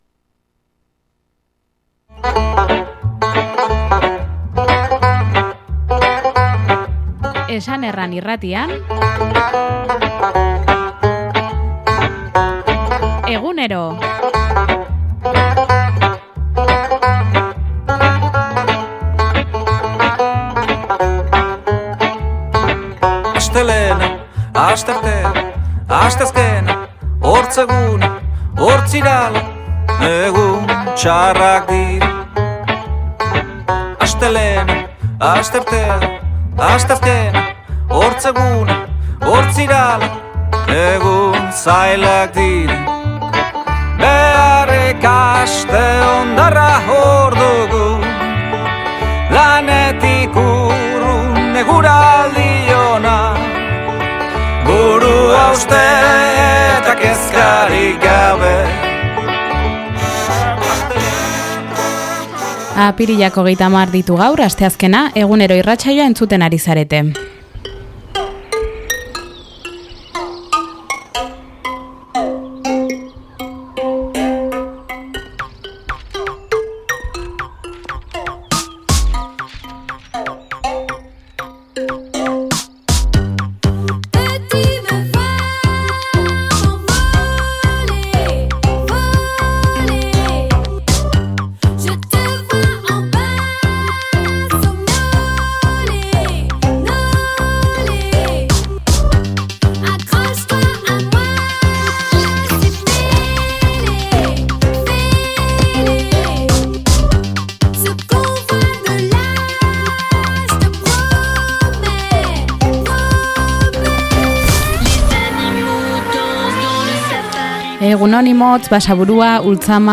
eskualdeko magazina